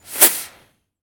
rocketaim.ogg